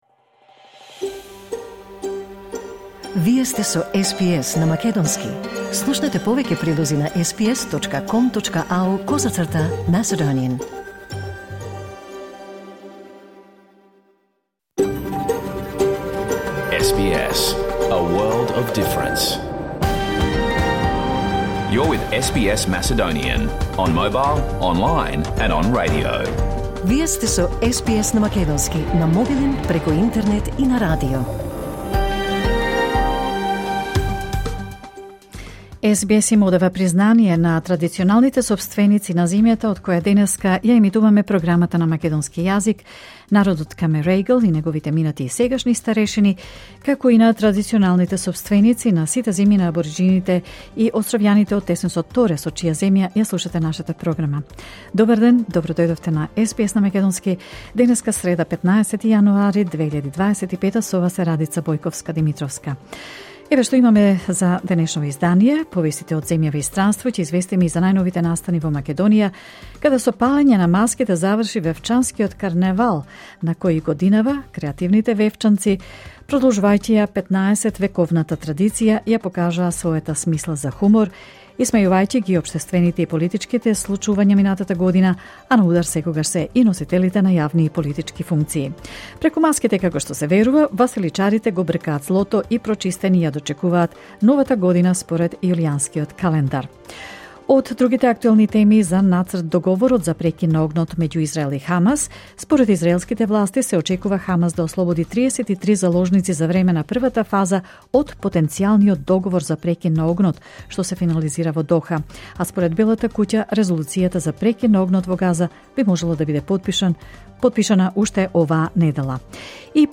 SBS Macedonian Program Live on Air 15 January 2025